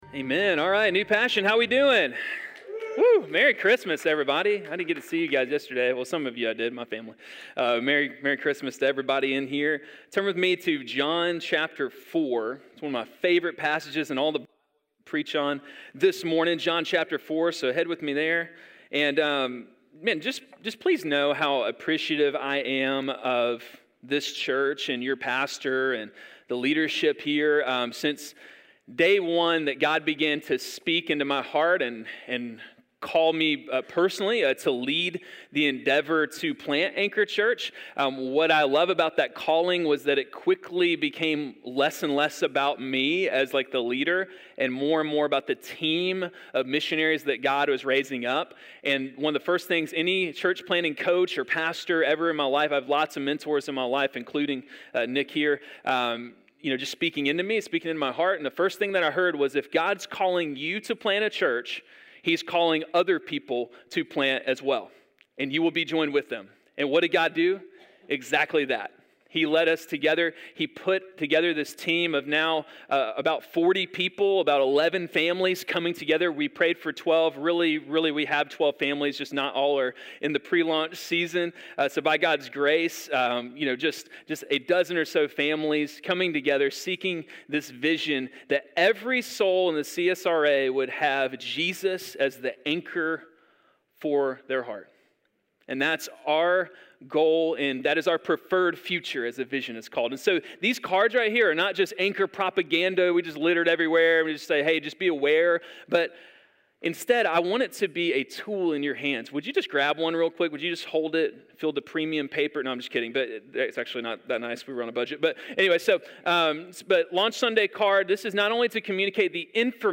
Solo sermons are stand alone sermons that do not belong to a particular series, or they are preached by a guest preacher.